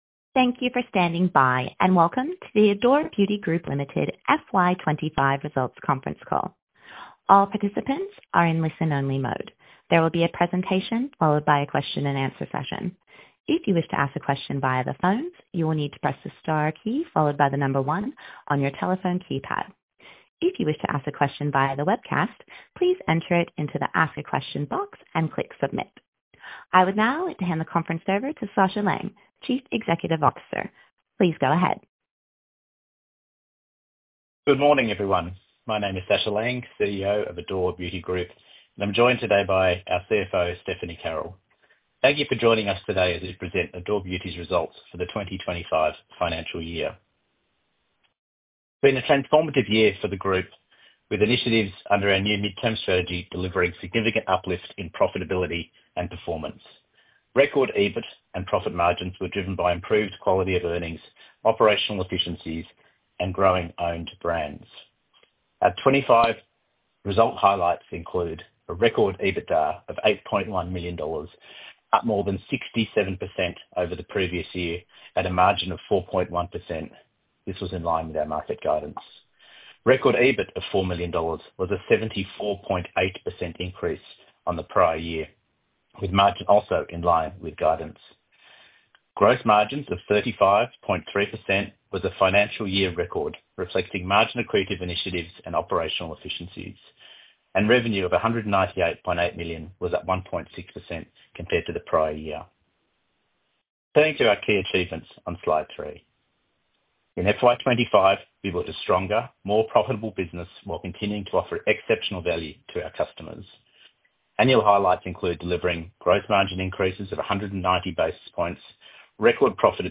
FY24 Results Conference call